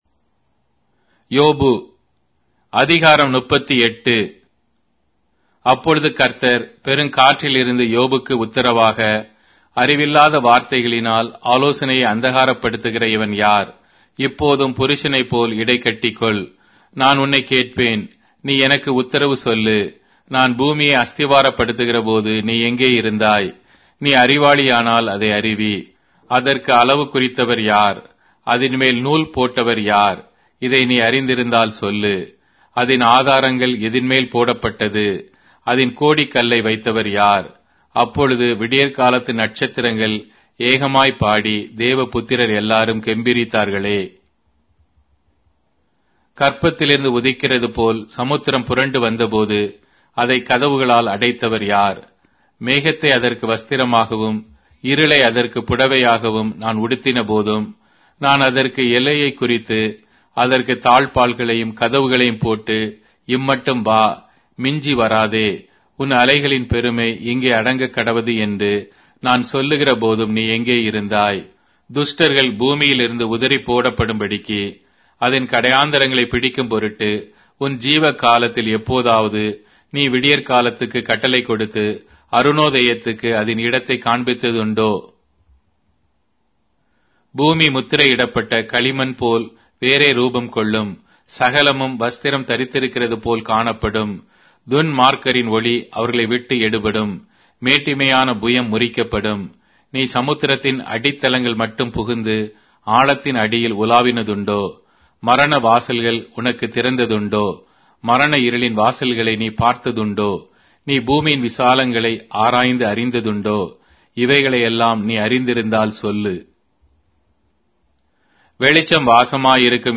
Tamil Audio Bible - Job 40 in Irvor bible version